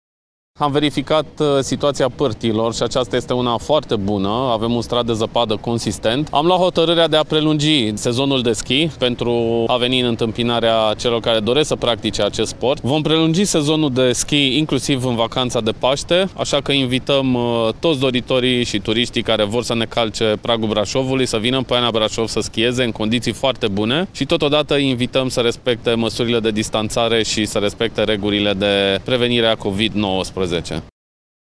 Viceprimarul municipiului Brașov, Sebastian Rusu.